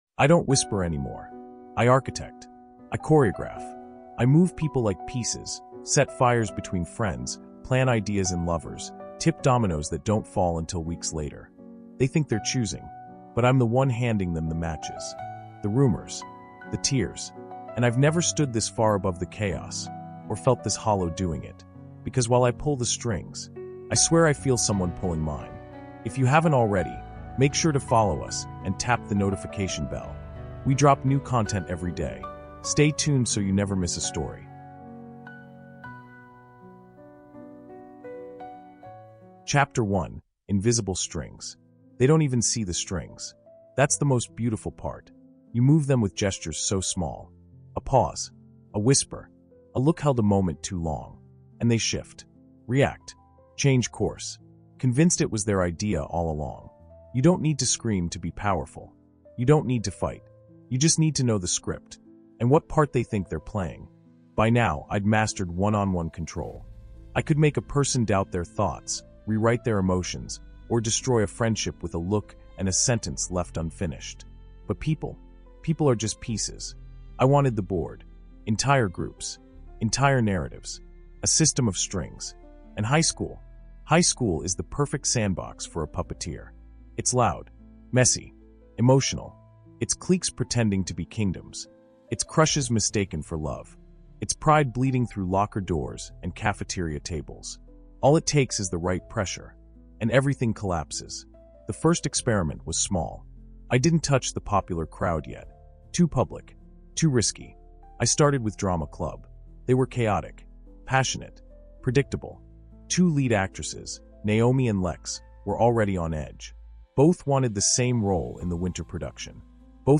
Inside the Mind of a Master Manipulator | The Puppetmaster | Audiobook